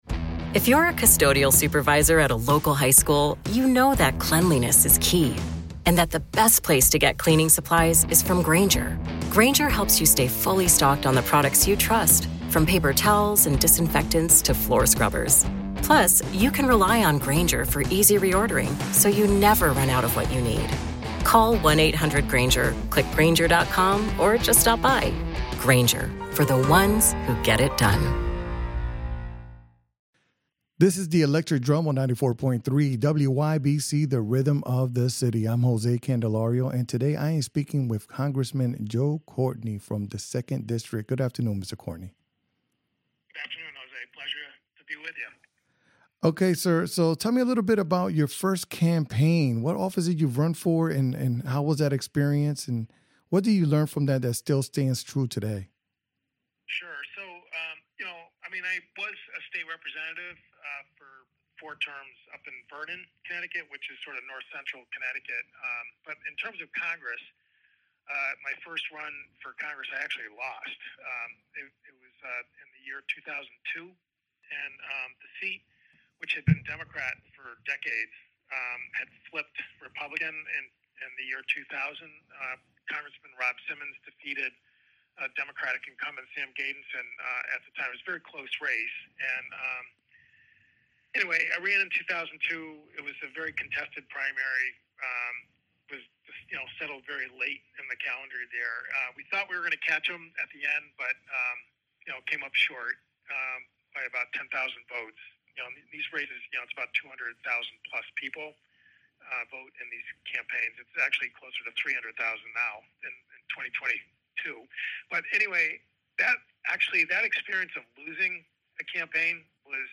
talks with United States Congressman Joe Courtney.